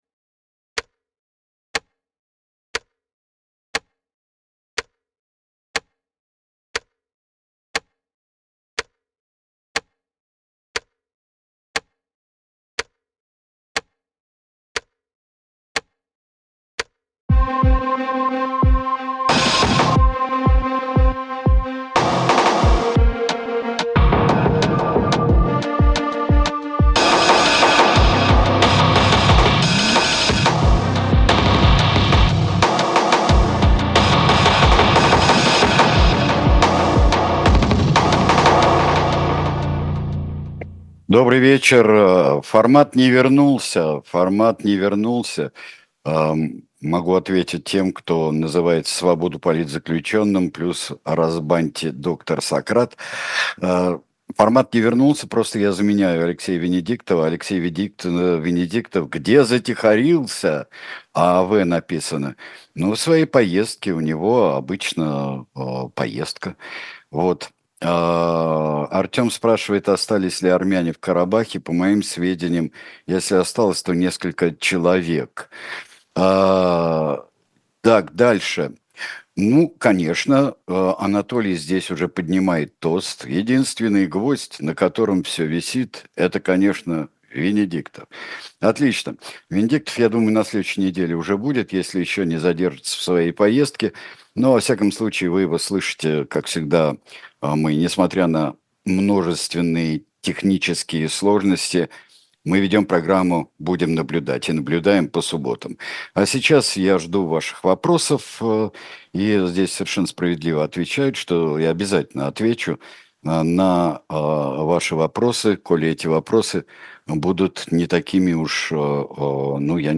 Сергей Бунтман в прямом эфире отвечает на ваши вопросы